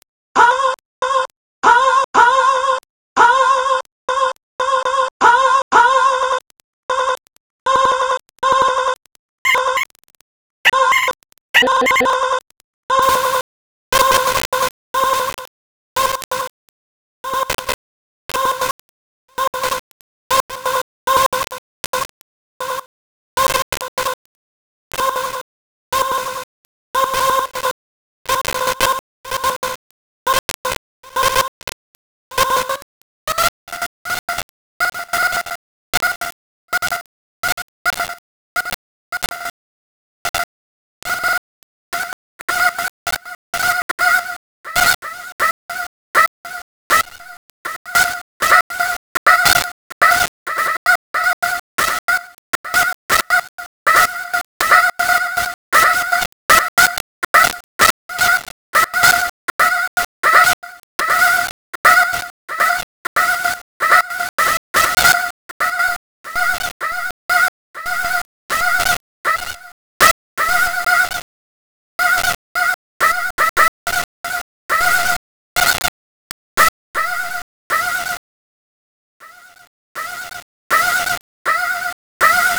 A few examples of sliced audio this patch can make (made those on the first take without any external editing):